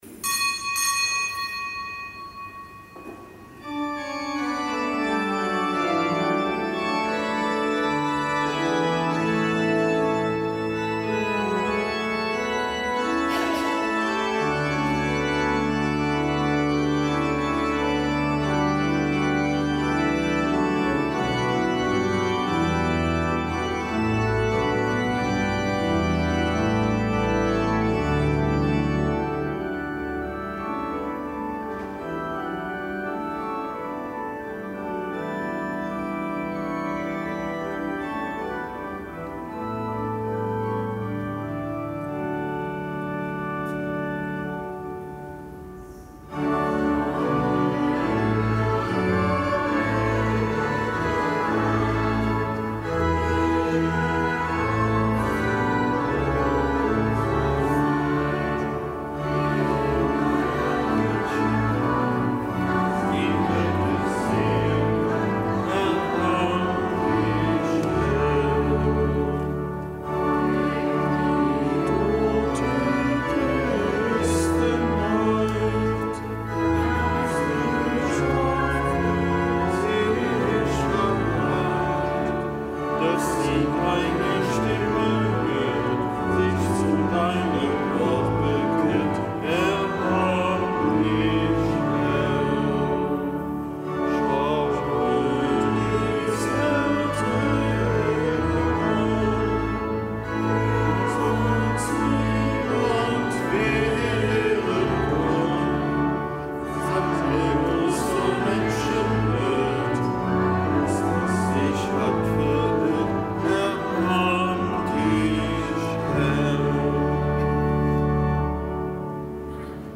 Kapitelsmesse am Montag der siebzehnten Woche im Jahreskreis
Kapitelsmesse aus dem Kölner Dom am Montag der siebzehnten Woche im Jahreskreis.